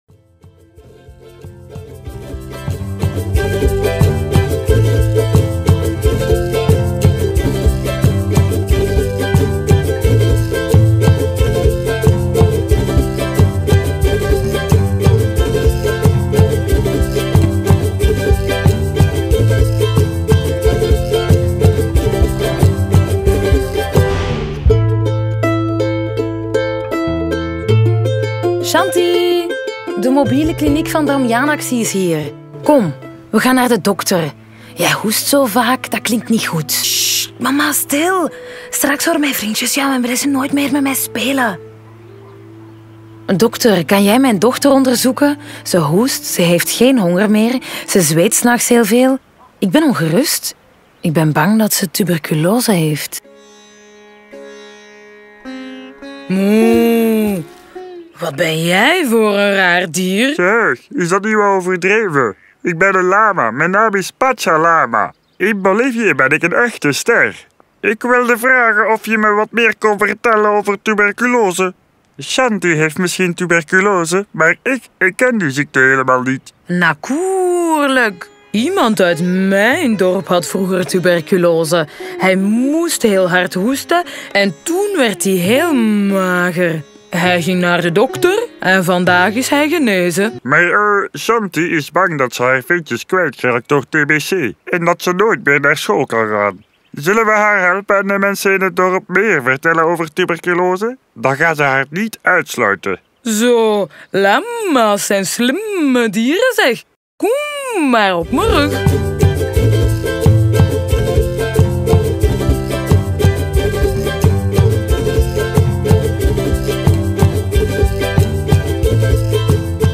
Diep, Jong, Speels, Veelzijdig, Warm